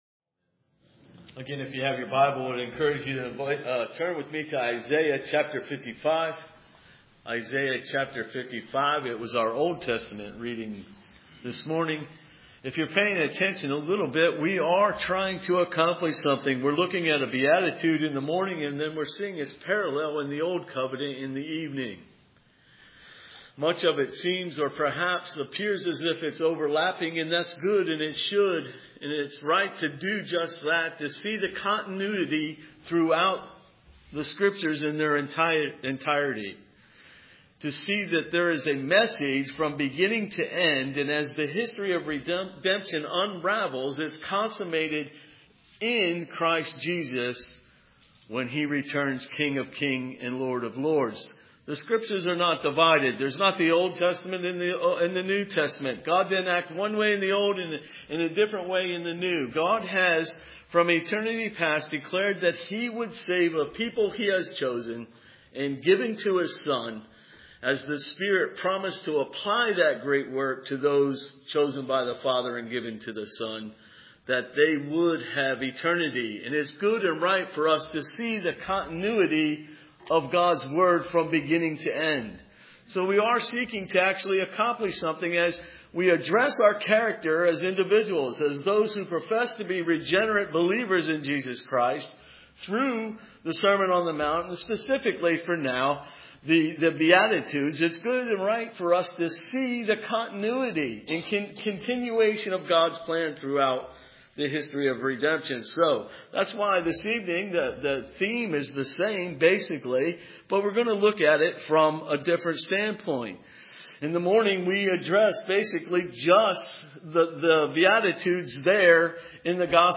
Isaiah 55:1-9 Service Type: Sunday Evening Isaiah 55:1-9 Christ is the feast of covenant grace.